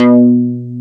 RDBASSE3.wav